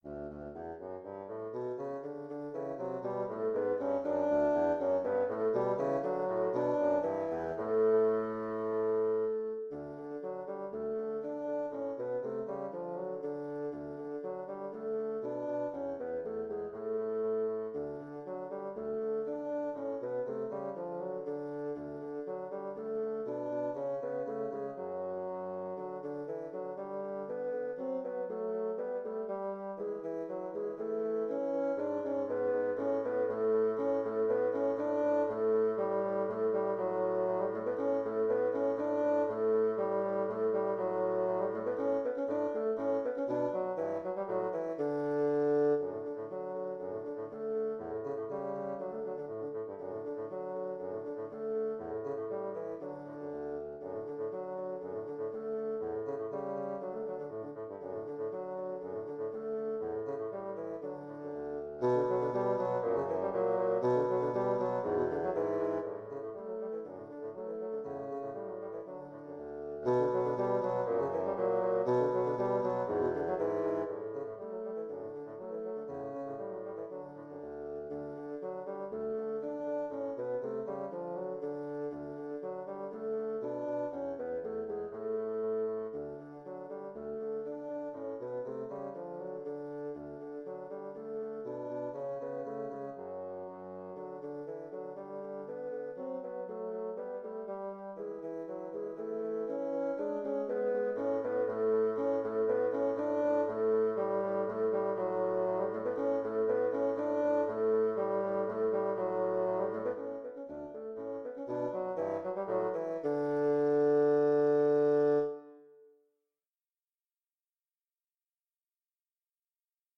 Intermediate bassoon duet
Instrumentation: bassoon duet
tags: bassoon music